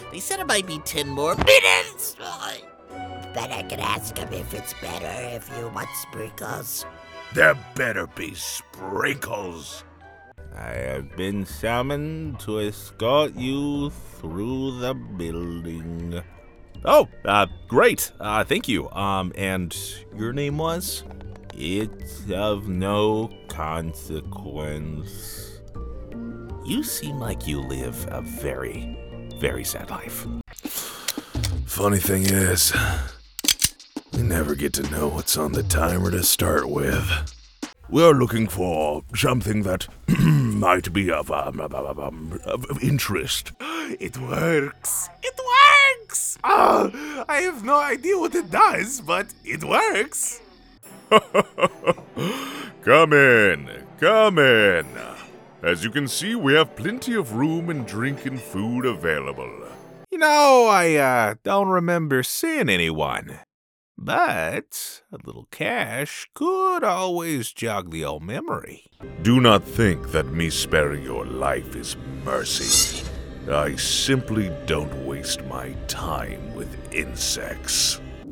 Character Sample